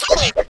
人声加武器挥动zth070511.wav
通用动作/01人物/03武术动作类/人声加武器挥动zth070511.wav
• 声道 單聲道 (1ch)